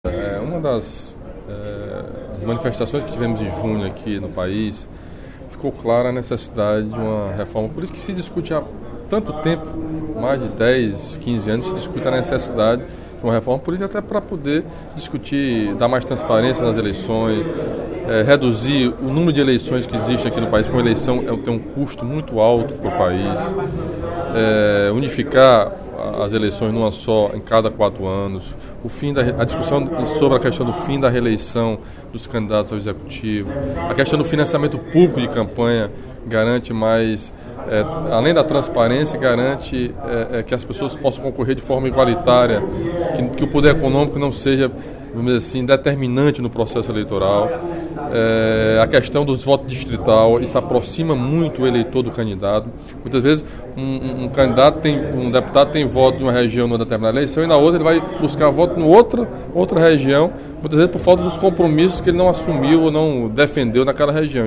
O deputado Camilo Santana (PT) destacou, no primeiro expediente da sessão plenária desta quinta-feira (07/11), a conclusão da redação final da proposta de reforma política pelo grupo de trabalho da Câmara dos Deputados. O parlamentar explicou que, entre os principais pontos da matéria, estão a unificação das eleições a cada quatro anos, o fim da reeleição e a instituição do voto facultativo no País.